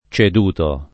cedere [©$dere] v.; cedo [©$do] — pass. rem. cedetti [©ed$tti] o cedei [©ed%i]; part. pass. ceduto [